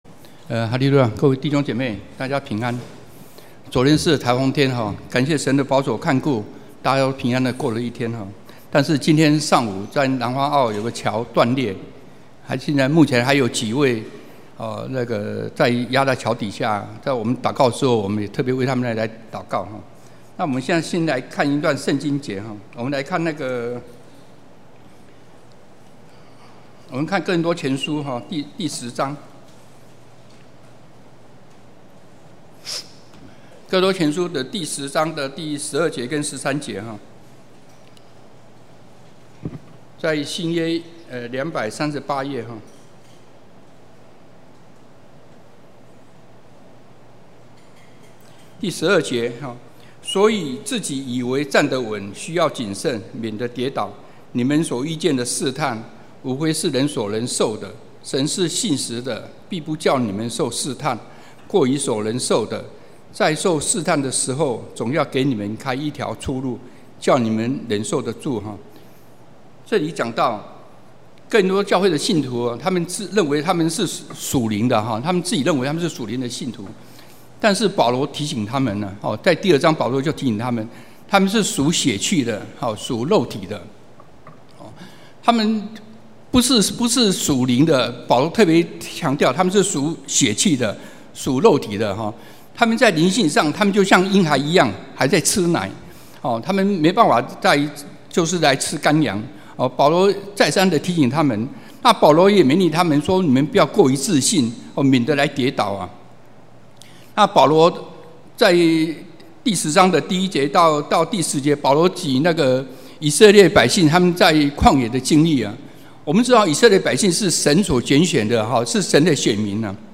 (見證會)